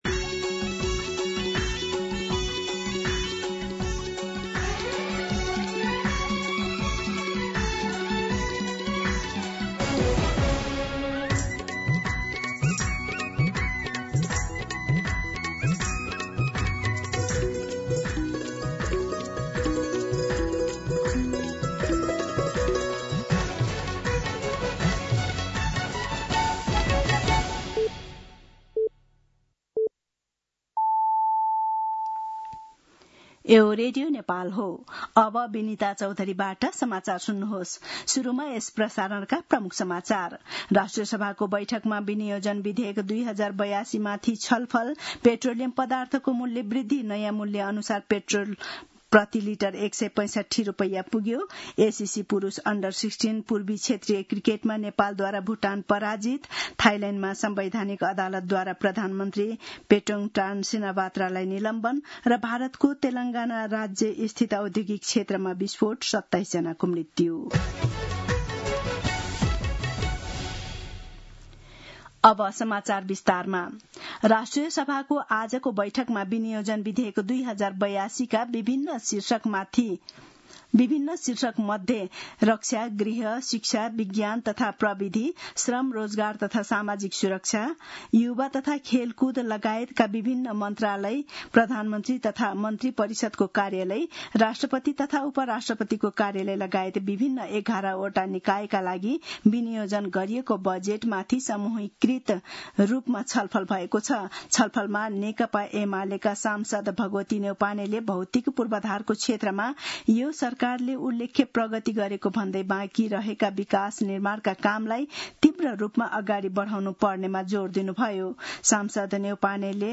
दिउँसो ३ बजेको नेपाली समाचार : १७ असार , २०८२